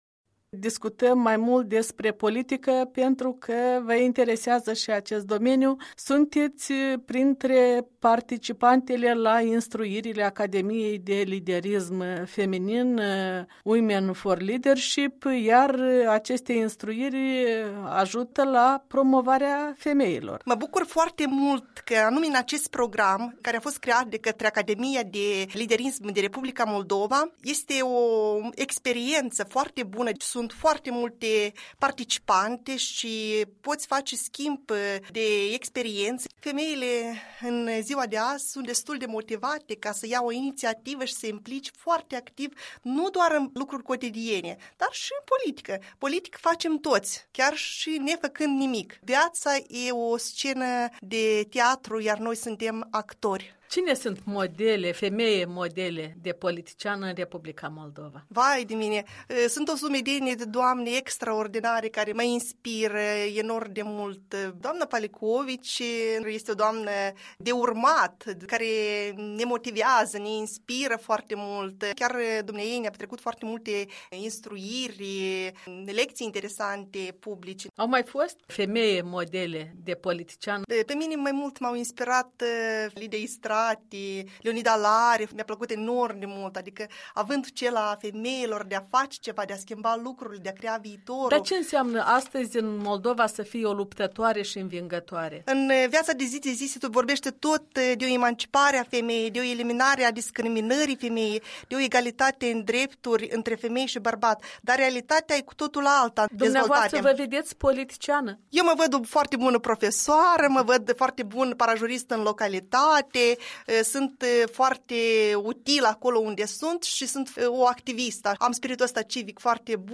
Un interviu despre situația actuală din Moldova cu o profesoară din comuna Hîrbovăț, Anenii Noi.